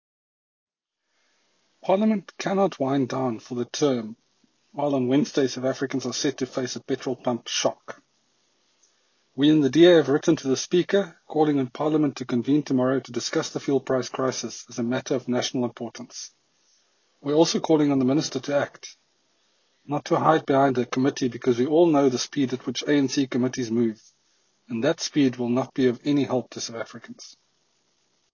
Afrikaans soundbite by Dr Mark Burke MP.